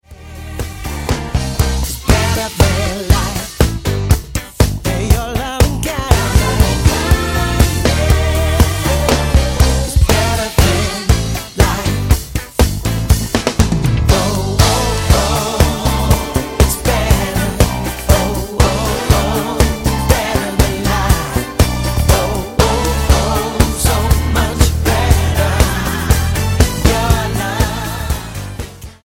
STYLE: Gospel
a retro soul feel